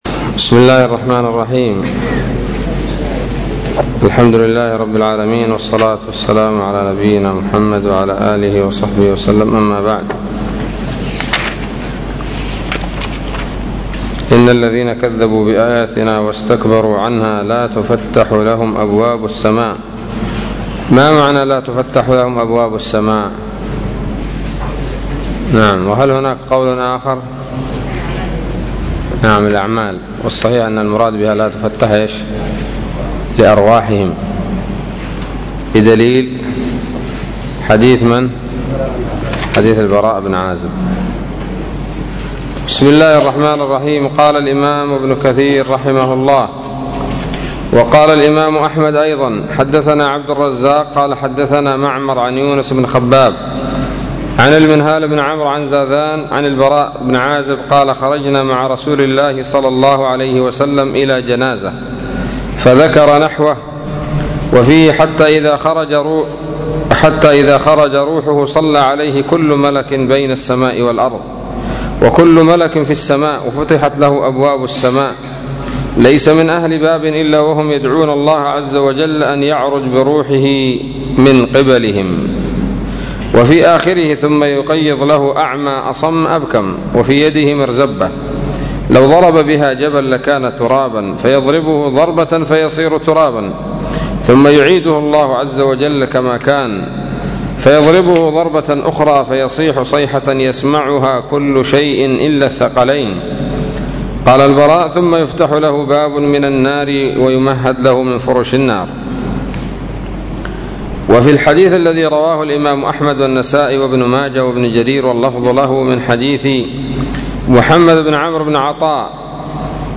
الدرس السادس عشر من سورة الأعراف من تفسير ابن كثير رحمه الله تعالى